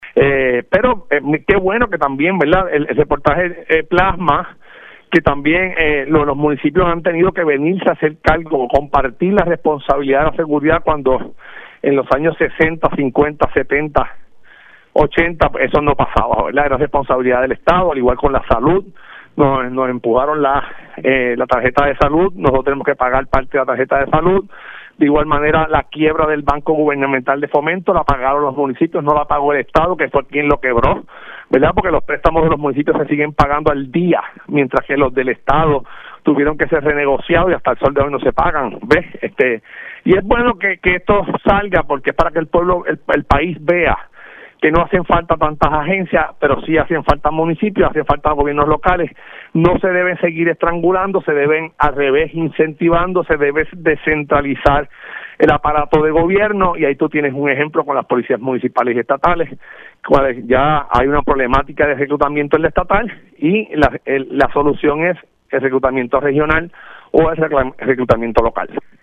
307-JUAN-CARLOS-GARCIA-PADILLA-ALC-COAMO-MUNICIPIOS-SE-HAN-HECHO-CARGO-DE-TODO-MIENTRAS-LE-QUITAN-DINERO.mp3